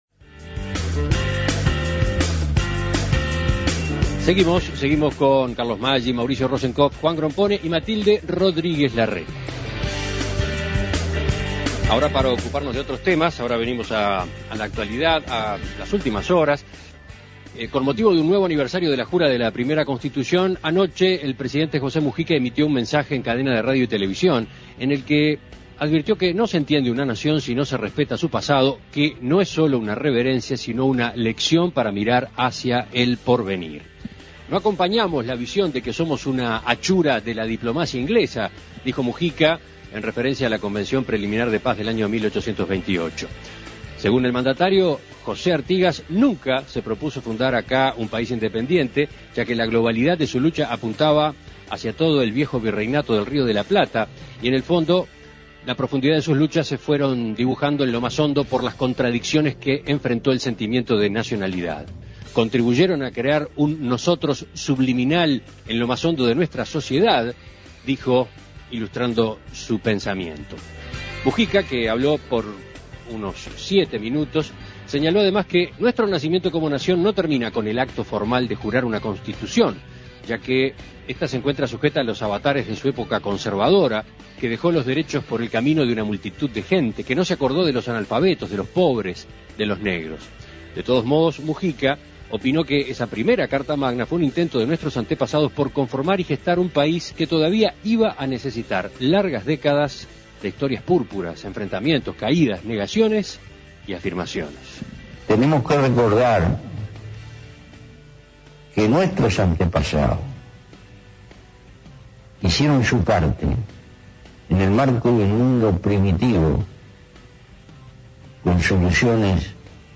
Con motivo de un nuevo aniversario de la jura de la primera Constitución, el presidente José Mujica emitió ayer un mensaje en cadena de radio y televisión.